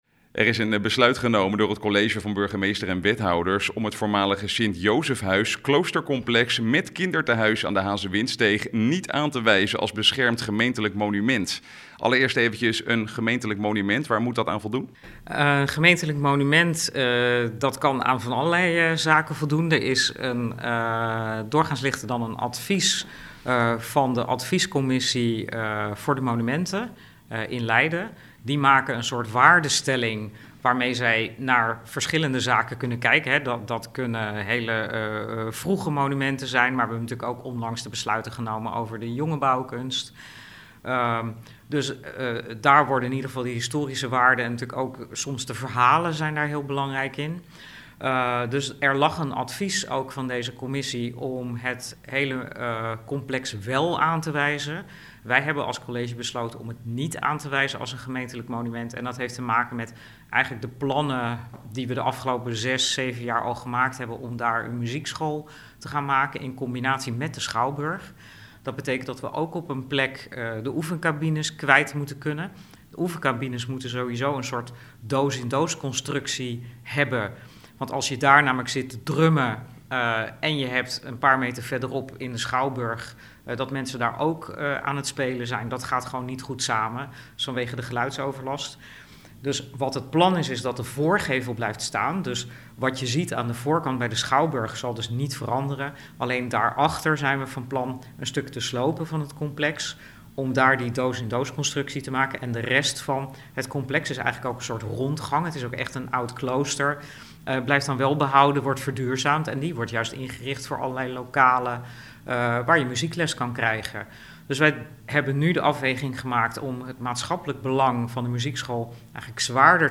Wethouder Yvonne van Delft over het niet toekennen van monumentenstatus voormalig klooster: